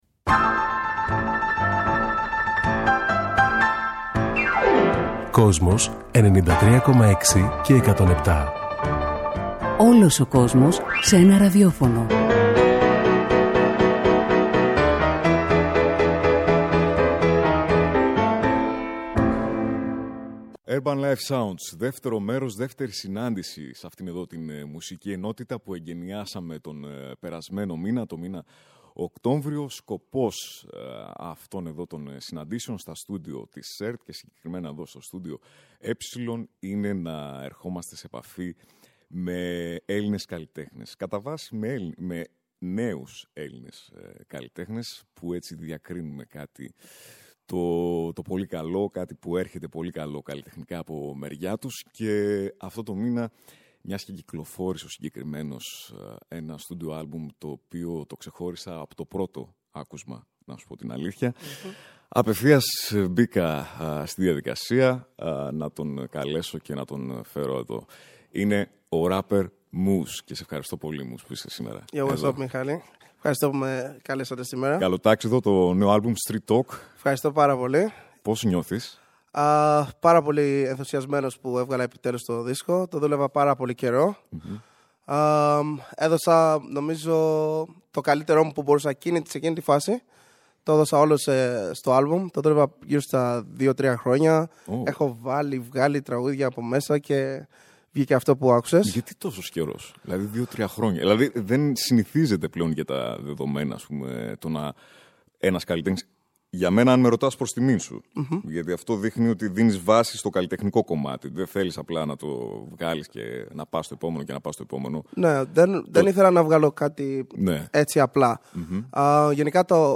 Αφροέλληνας ράπερ